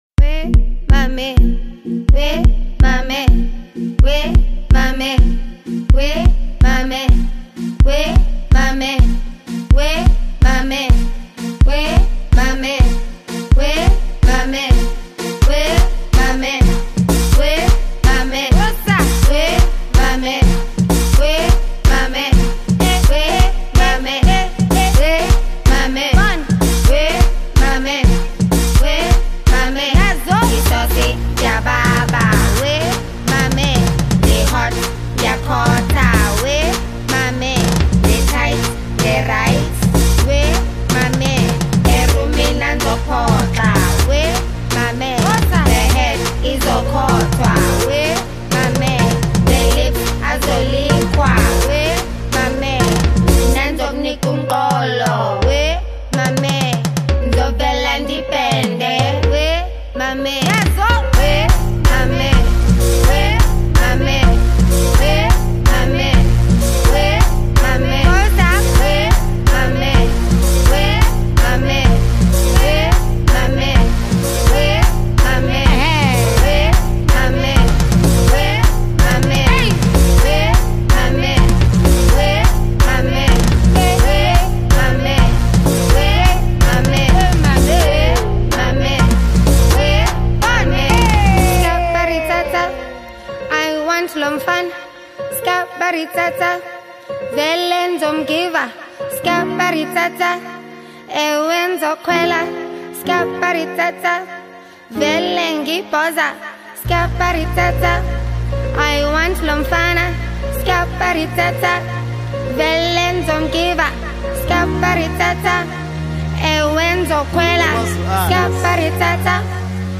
ghetto-funk Gqom dance tune
was recorded at the Red Bull Studios Cape Town